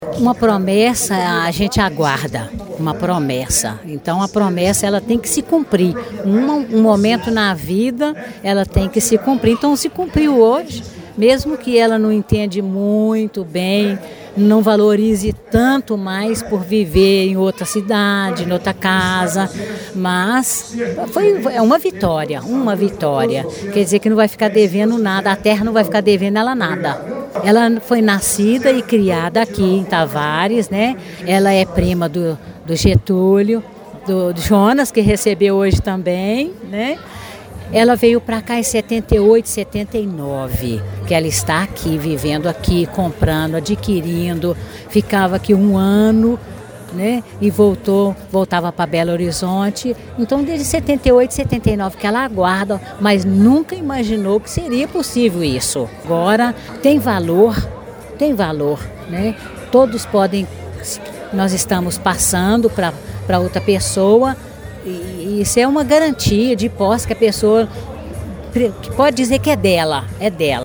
Relatos colhidos durante o evento traduzem o impacto social da medida: